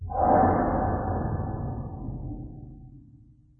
Звук глухой удак по металу.